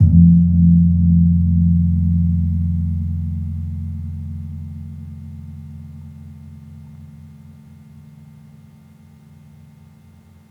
Gamelan
Gong-F1-p.wav